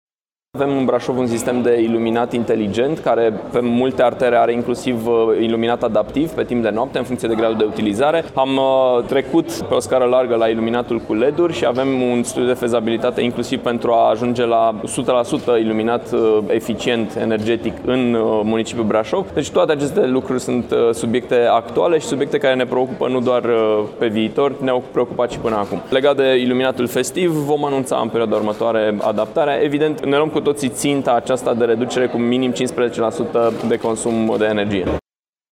La rândul său, primarul Brașovului, Allen Coliban dă asigurări că orașul de la poalele Tâmpei face economie de energie, inclusiv în perioada sărbătorilor de iarnă, când iluminatul festiv va fi redus: